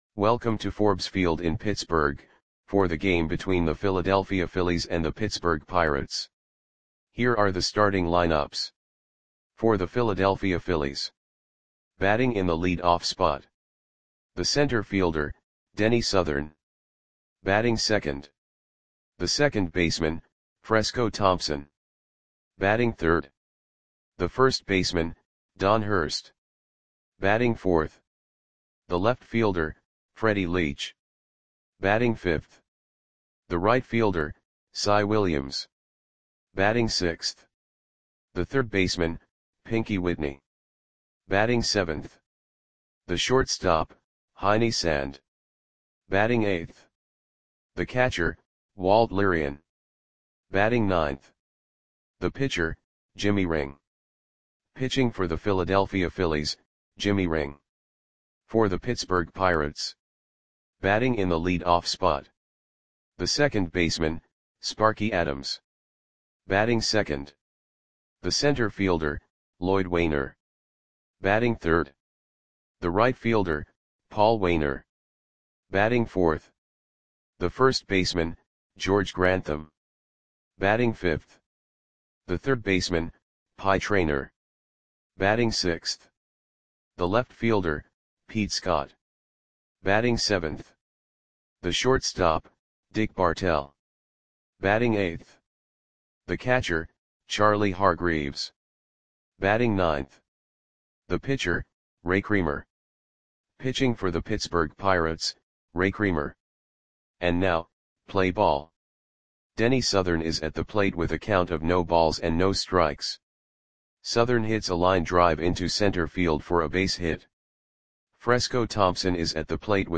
Audio Play-by-Play for Pittsburgh Pirates on July 10, 1928
Click the button below to listen to the audio play-by-play.